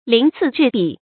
lín cì zhì bǐ
鳞次栉比发音
成语正音栉，不能读作“jié”。